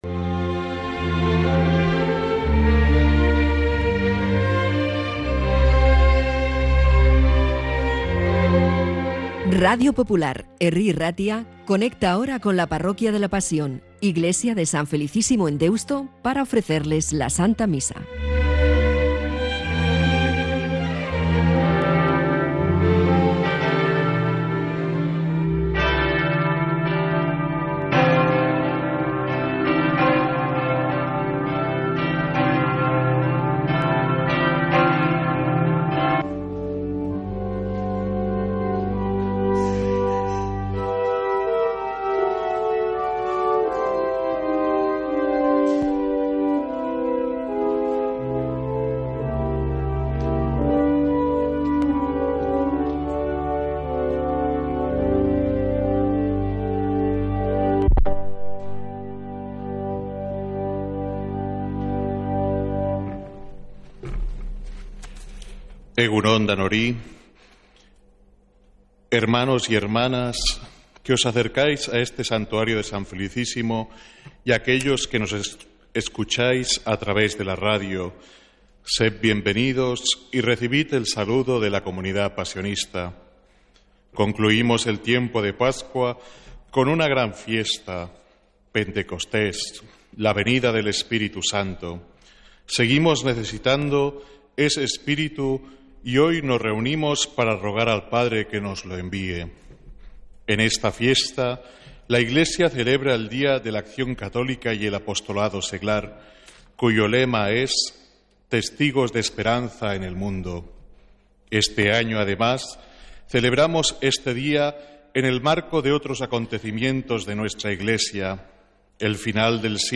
Santa Misa desde San Felicísimo en Deusto, domingo 8 de junio